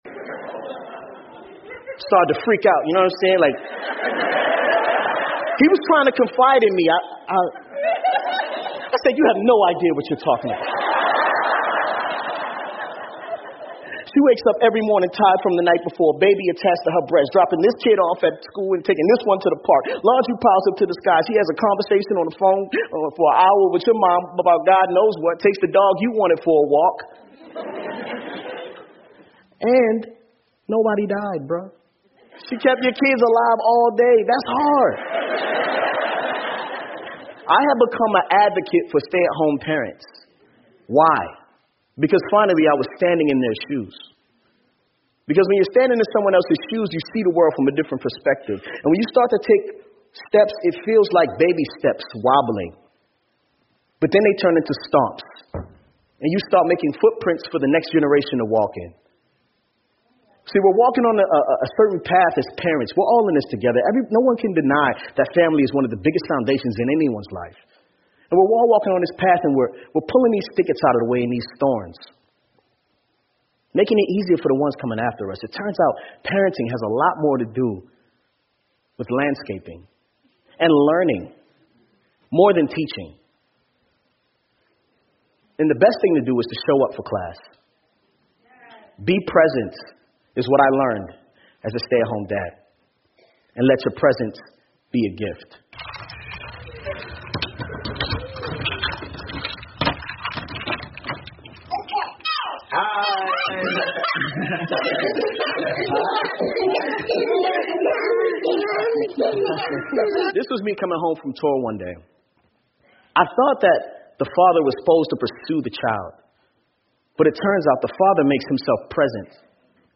TED演讲:我从全职奶爸经历中学到的知识(6) 听力文件下载—在线英语听力室